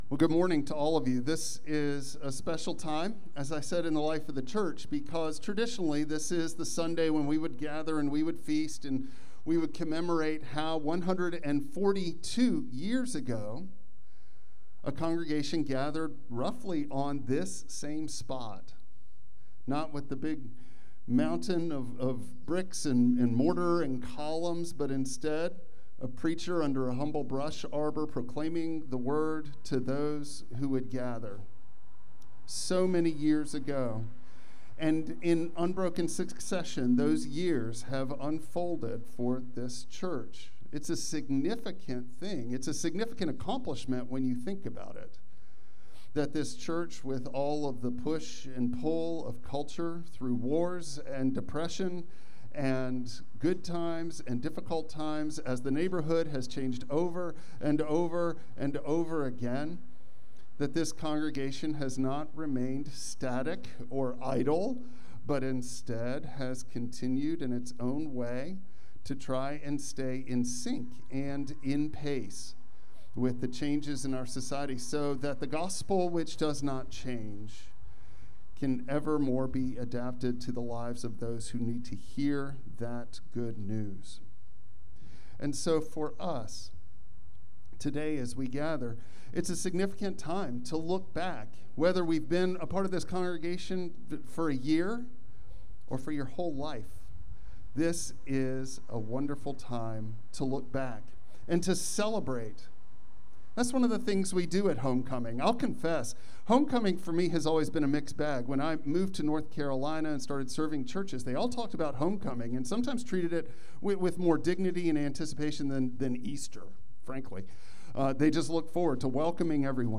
Philippians 3:4-14 Service Type: Traditional Service Bible Text